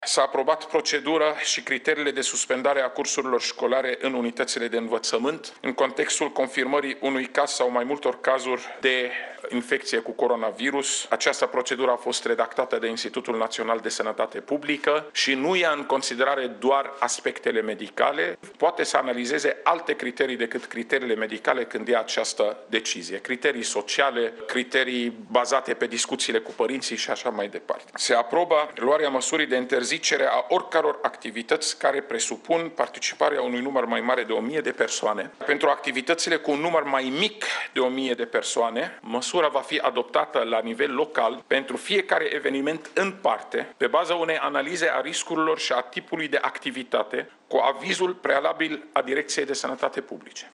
Raed Arafat a declarat, la sediul Ministerului de Interne, că toate şcolile în care va fi confirmat un caz de coronavirus vor fi închise.